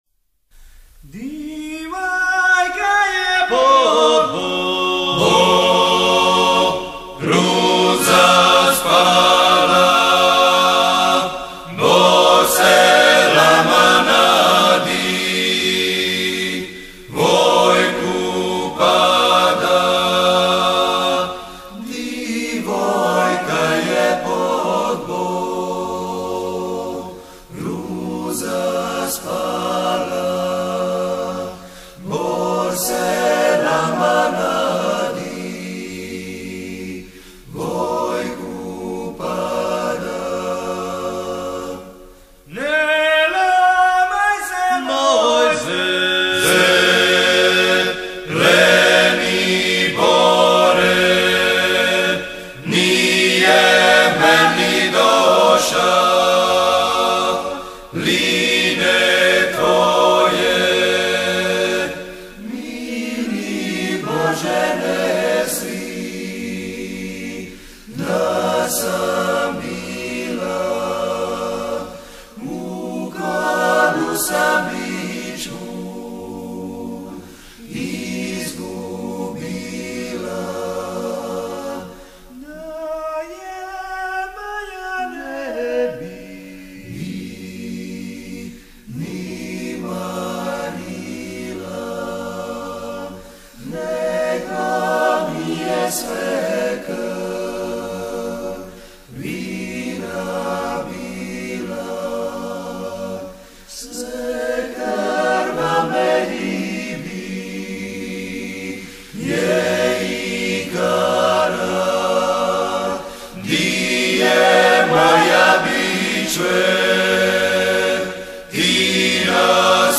I. Tenor
Bariton
I. Bas